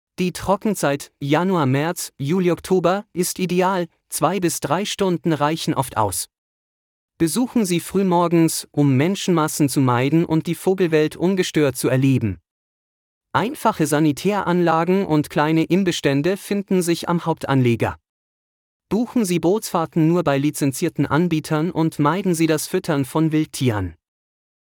🎧 Verfügbare Audioguides (2) Guide für emotionale Erfahrungen (DE) 🔗 In neuem Tab öffnen Praktische Informationen (DE) 🔗 In neuem Tab öffnen